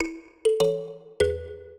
mbira
minuet6-9.wav